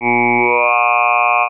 They are linked together using linseg in CSound. This joins together two different values for each formant with a straight line (ramp). So first you hear 'Ooh', then the sound changes to ahh as the formants are moved into their new position, then you hear 'ahh'.
ooahh.wav